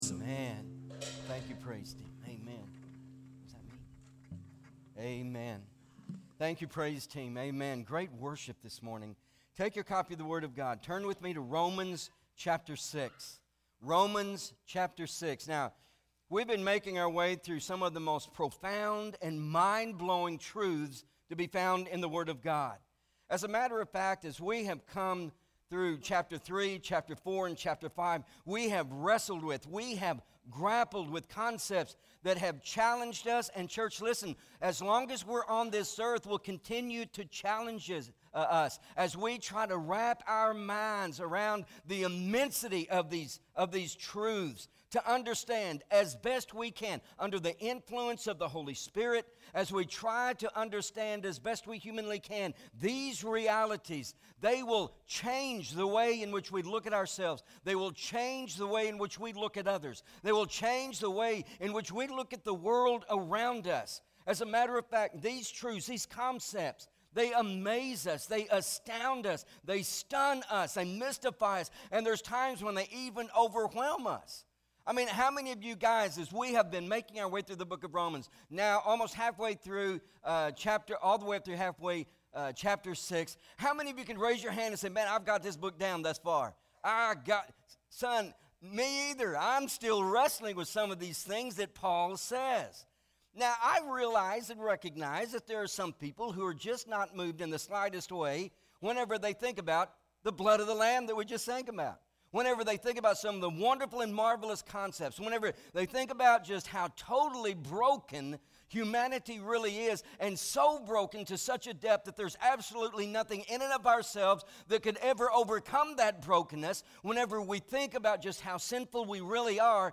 Romans Revealed- Dead to Sin- Alive to God- Conclusion MP3 SUBSCRIBE on iTunes(Podcast) Notes Sermons in this Series Romans 8: 12-14 Not Ashamed!